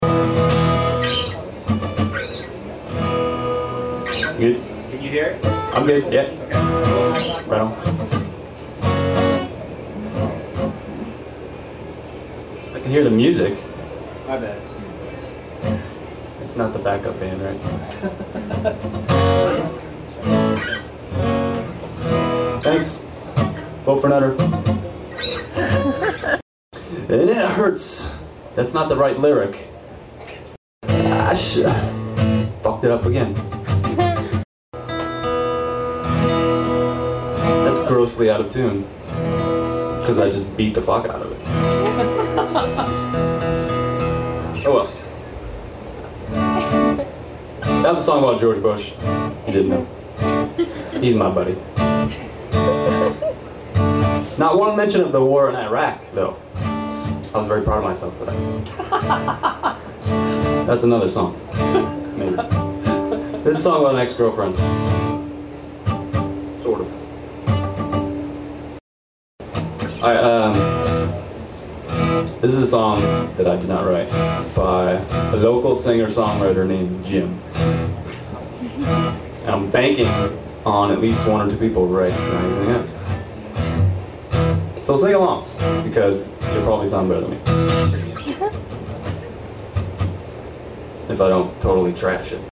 chopped-up-and-spliced-together MP3 of its own (1.5 MB), including the two small sections I cut out of that version of Whiskey.